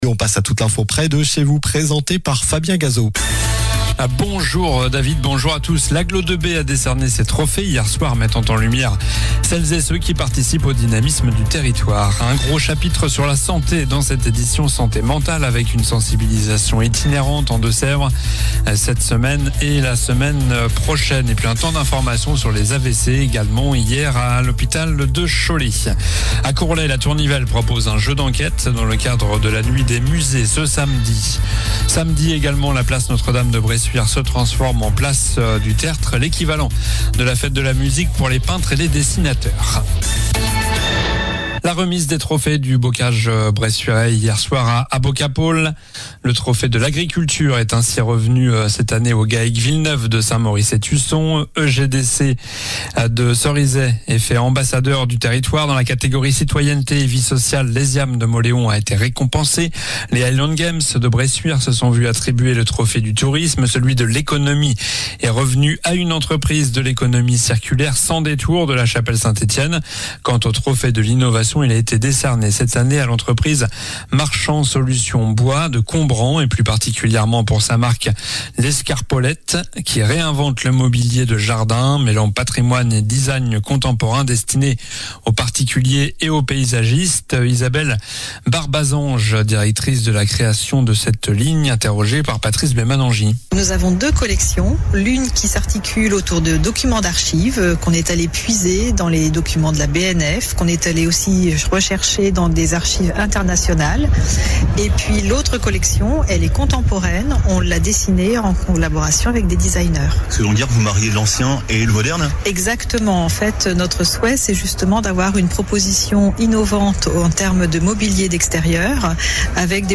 Journal du vendredi 16 mai (midi)